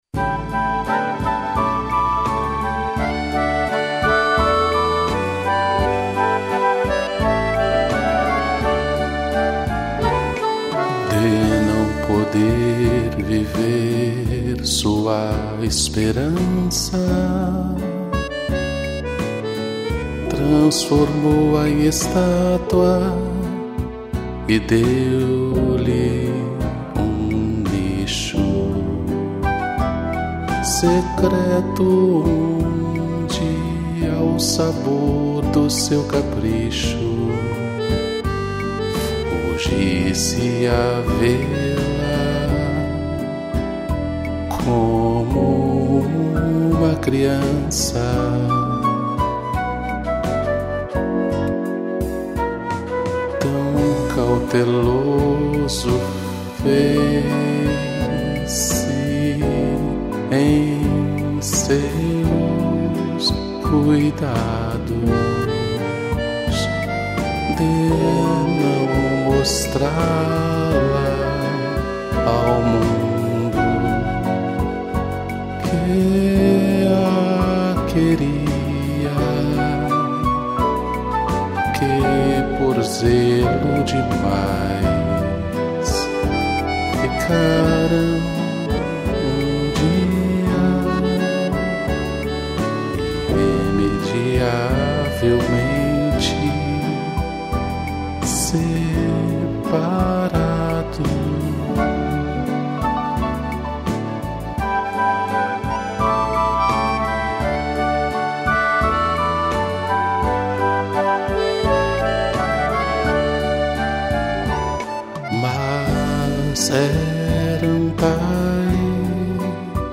piano, flauta, acordeão e cello